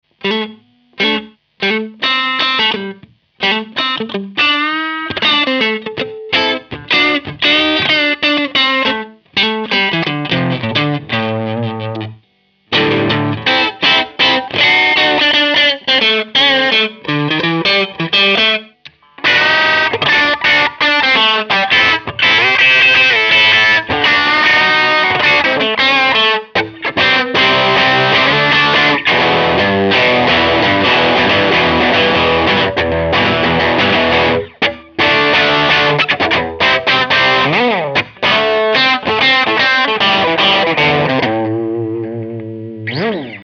ыы, я овердрайв спешл собирал когда-то...
drive_medium.mp3